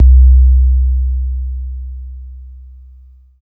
88 808 BASS.wav